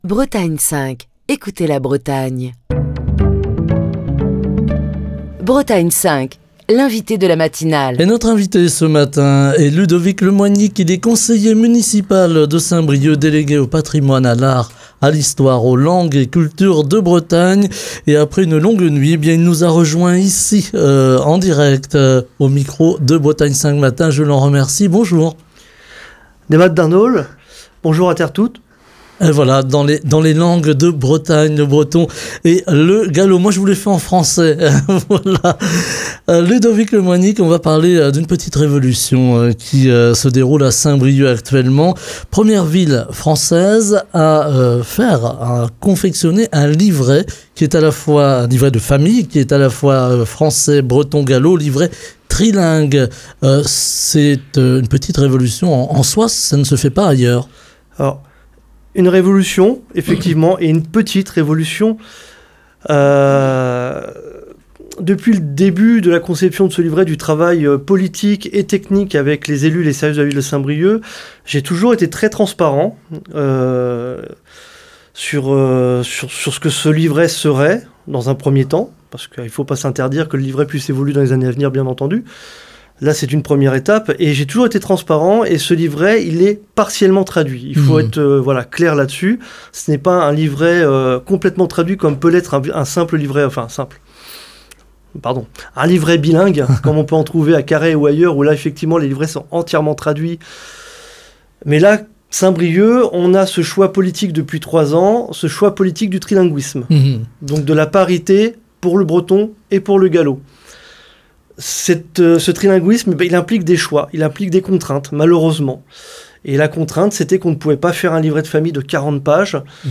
Nous en parlons ce matin avec Ludovic Le Moignic, conseiller municipal de Saint-Brieuc, délégué au patrimoine à l'art, à l'Histoire, aux langues et cultures de Bretagne, qui est l'invité de la matinale de Bretagne 5.